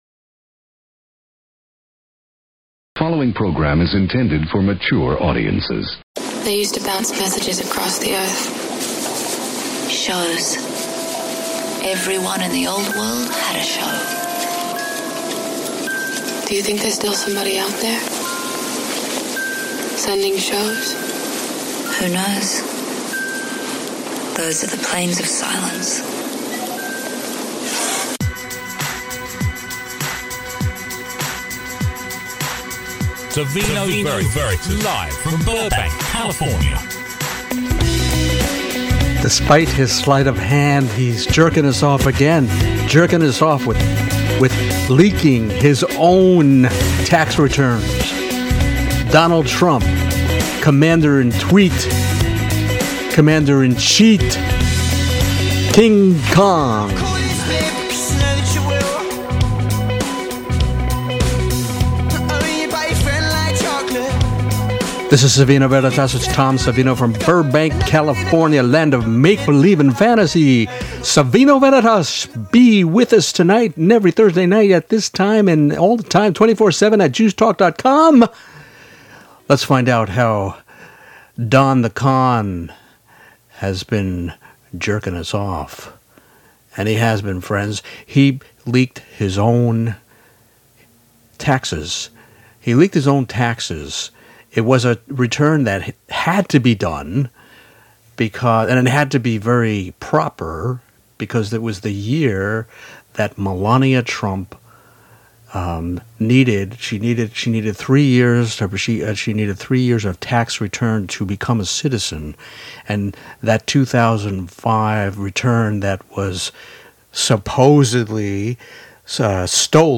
For St. Patrick’s Day, Veritas is pleased to include pertinent clips from Conan O’Brien’s late night talk show.